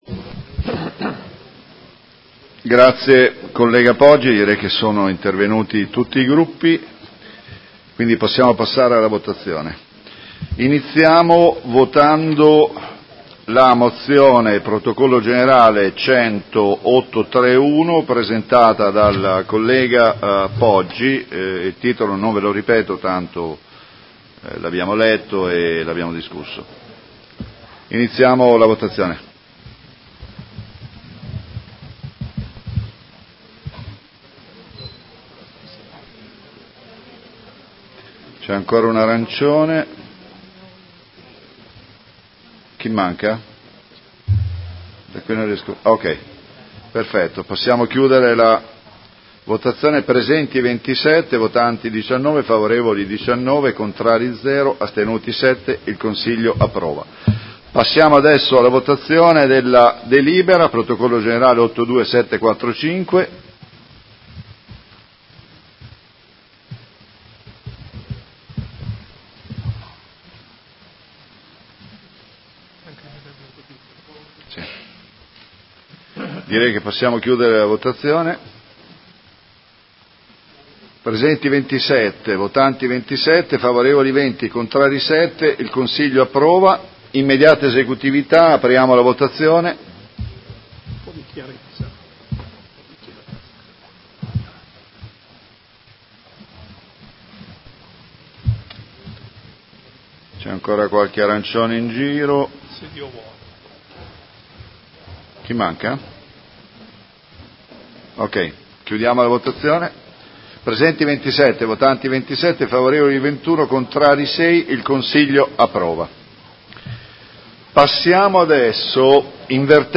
Seduta del 04/04/2019 Come Presidente di turno mette ai voti la Mozione nr. 100831: approvata.